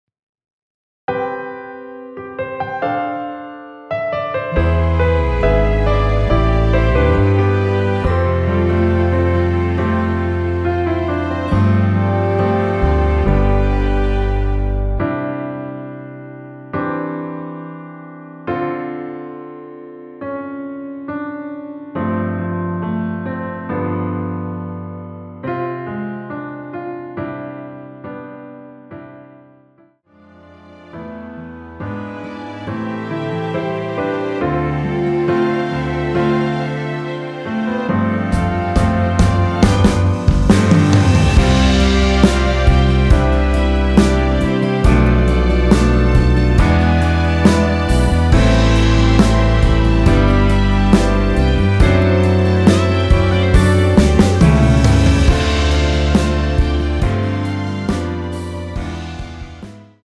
원키(1절+후렴)으로 진행되는 MR입니다.(본문의 가사와 미리듣기 확인)
앞부분30초, 뒷부분30초씩 편집해서 올려 드리고 있습니다.
중간에 음이 끈어지고 다시 나오는 이유는